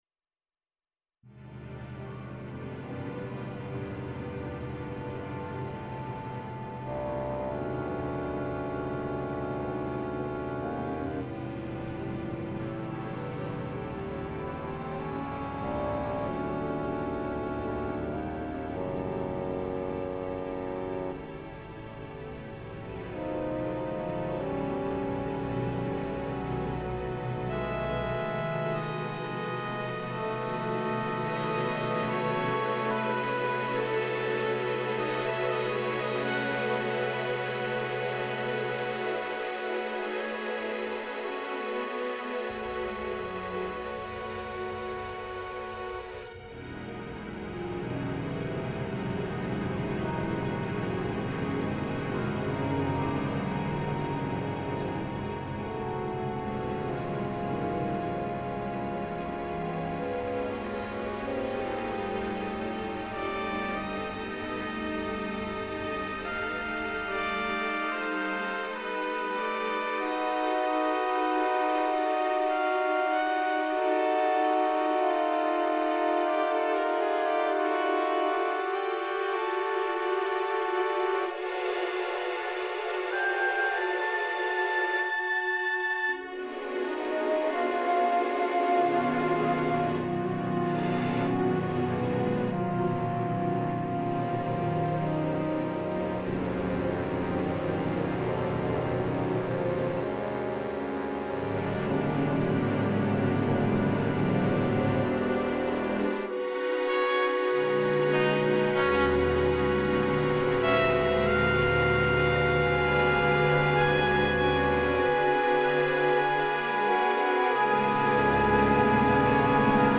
symphony orchestra